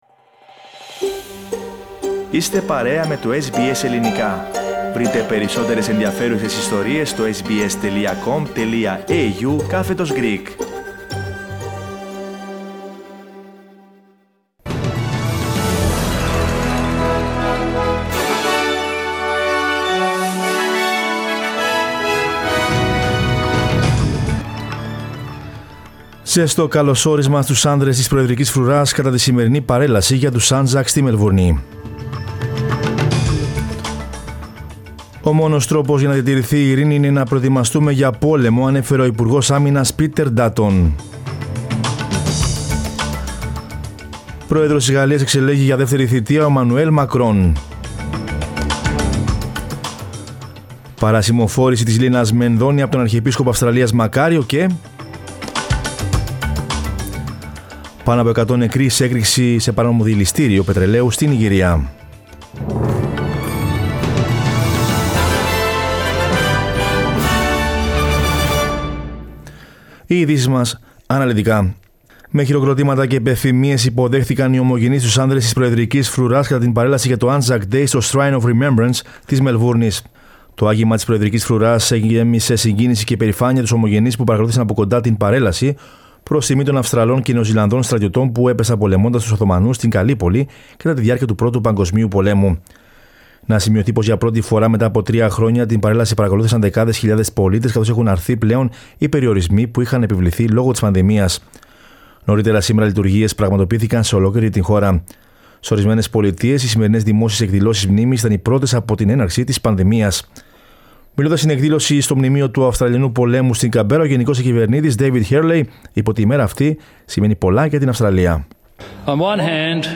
Δελτίο Ειδήσεων Δευτέρα 25.04.22
News in Greek. Source: SBS Radio